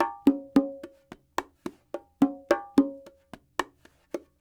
44 Bongo 11.wav